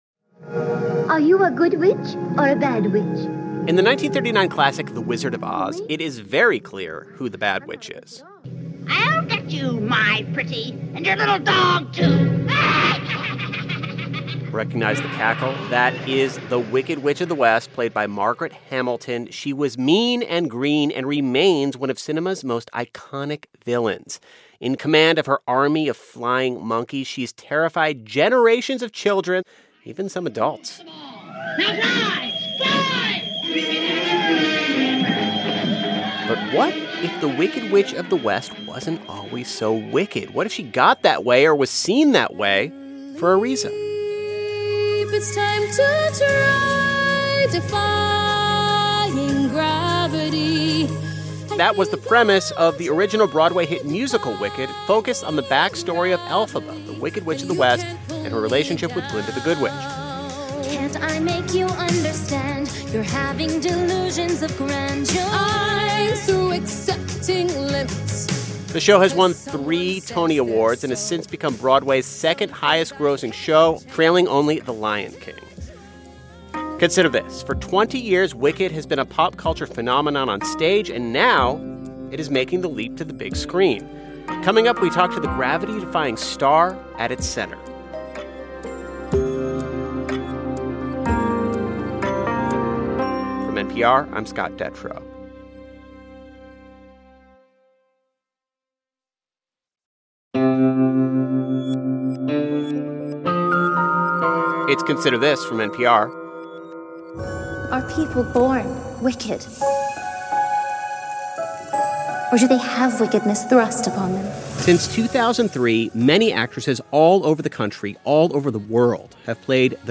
Stage and screen star Cynthia Erivo plays the Wicked Witch of the West. She speaks to NPR about the role.